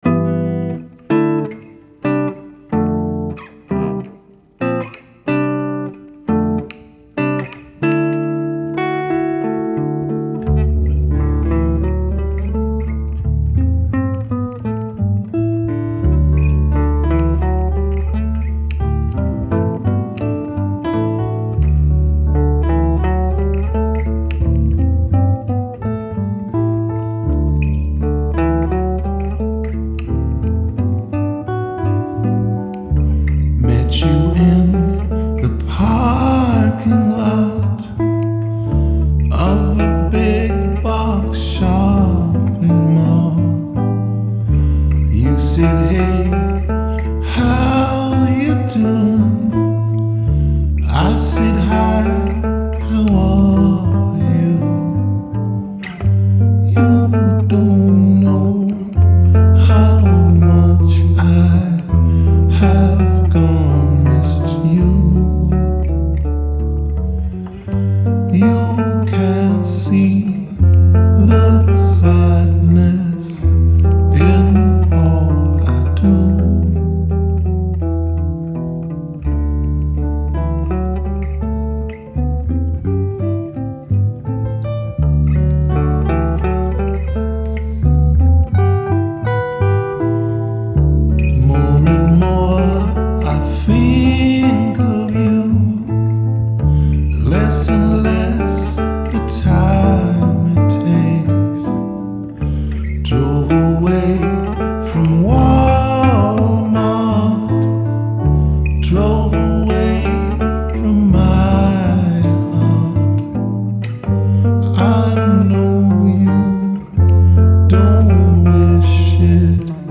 Rehearsals 17.3.2012